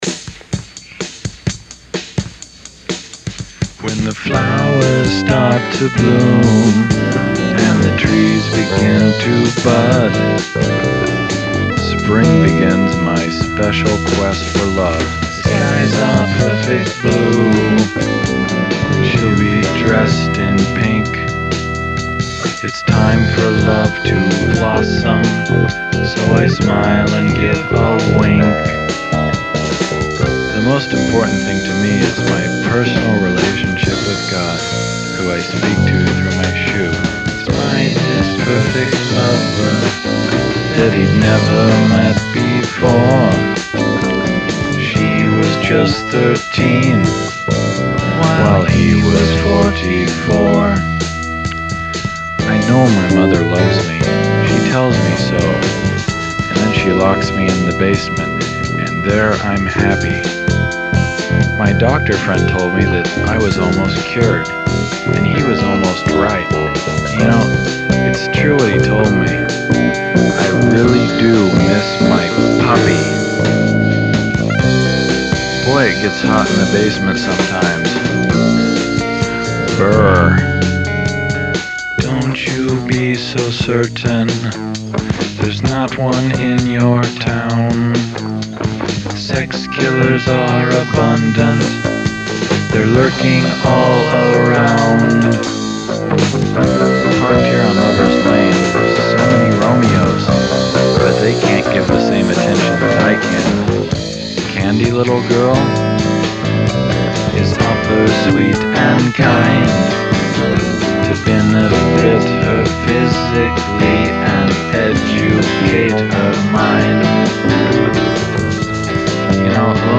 synth